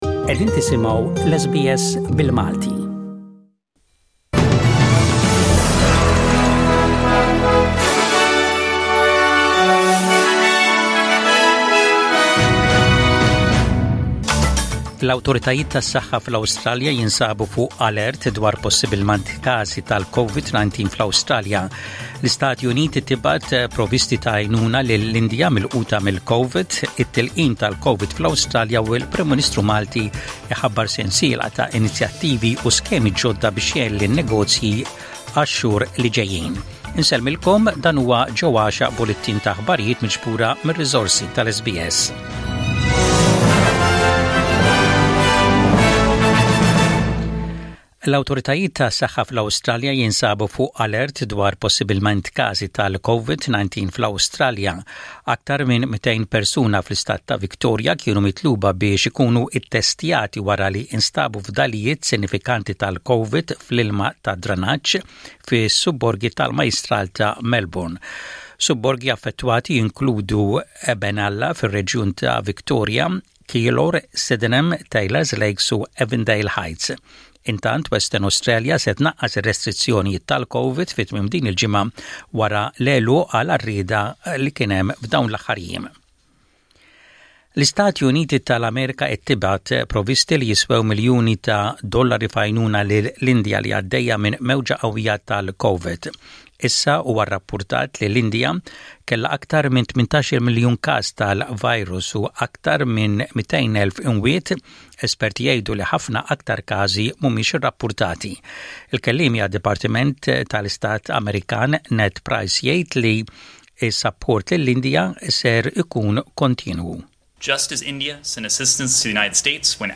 SBS Radio | Maltese News: 30/04/21